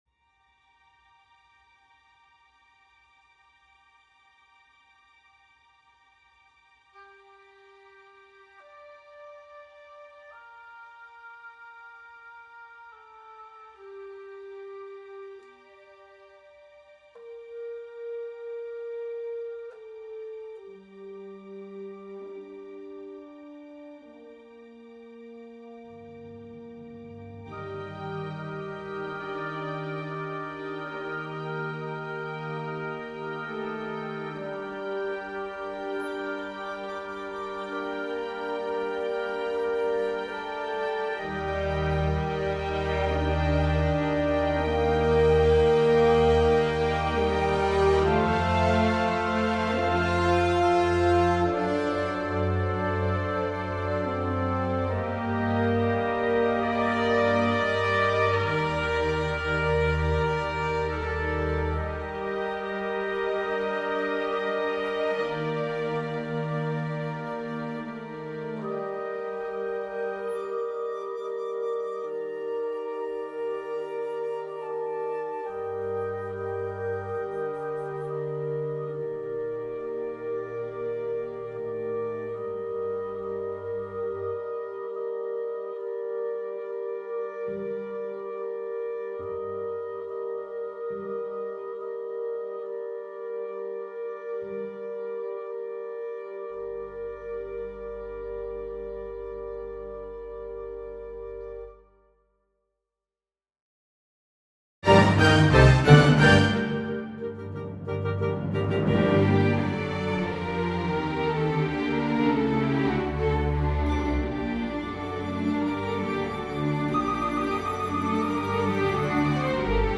Fantasia on a Bach theme - Looking for feedback - Orchestral and Large Ensemble - Young Composers Music Forum